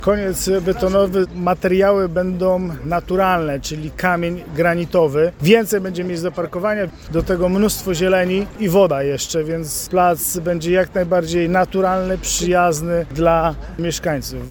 Burmistrz Robert Czapla mówi, że w pierwszej kolejności wymieniona zostanie infrastruktura podziemna, docelowo przestrzeń ma zupełnie zmienić charakter.